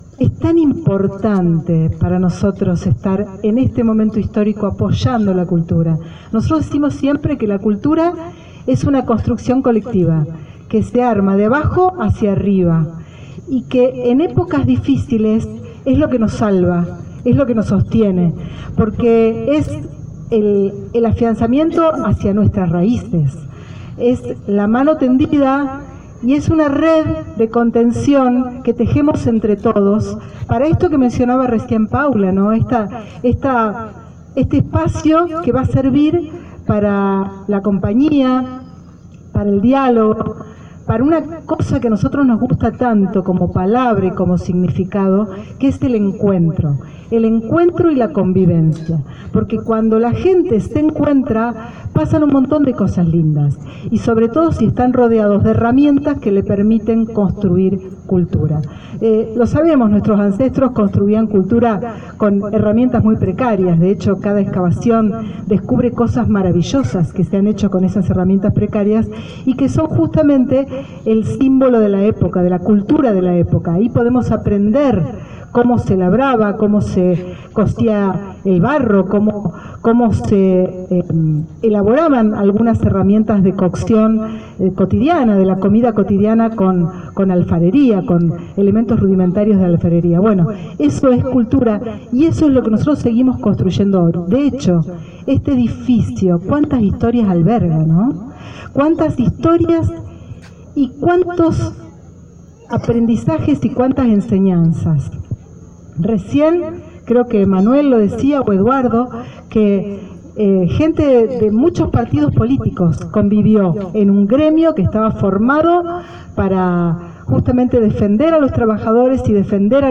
Se inauguró “La Casa de la Cultura”
Ministra de Cultura del Gobierno de Santa Fe – Susana Rueda